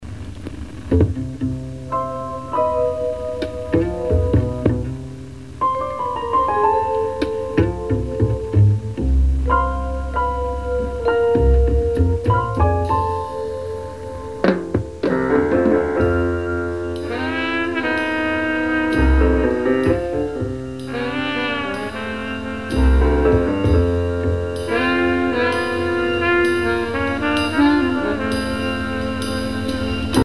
28k 56k 100k _____________ Thème musical